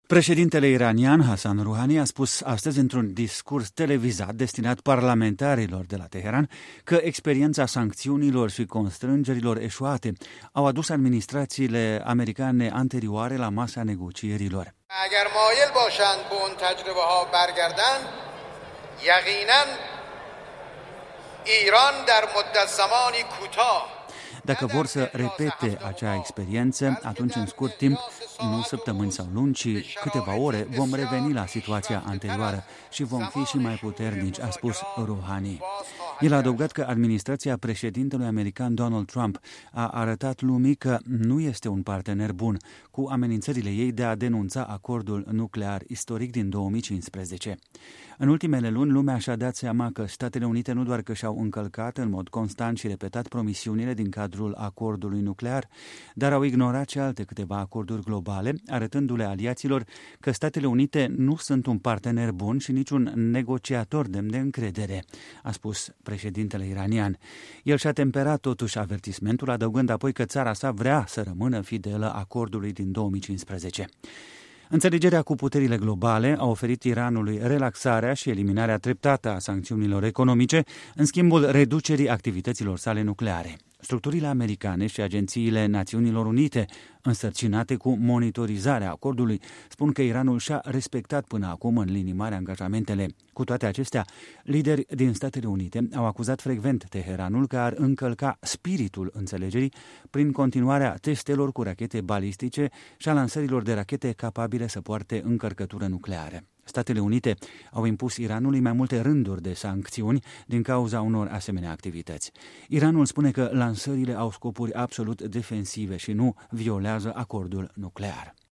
Președintele iranian Hassan Rouhani a spus marți într-un discurs televizat destinat parlamentarilor din țara sa că „experiența sancțiunilor și constrângerilor eșuate au adus administrațiile americane precedente la masa negocierilor”.